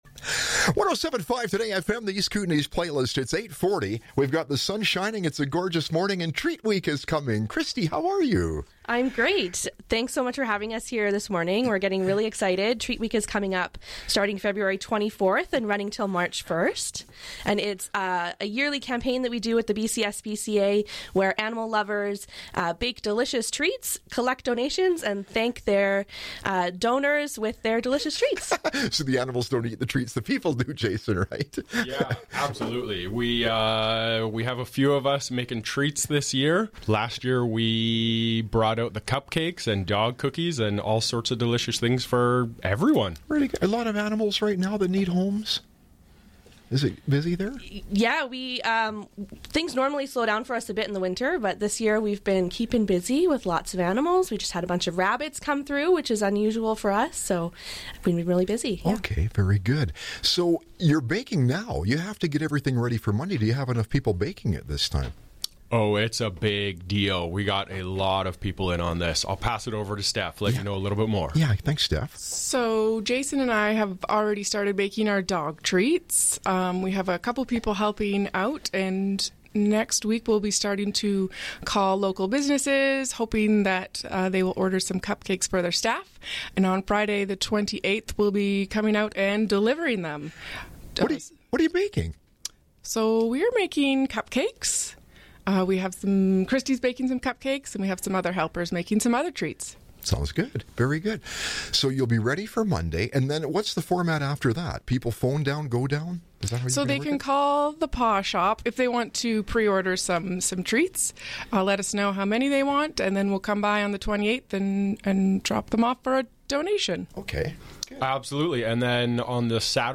Full radio interview below.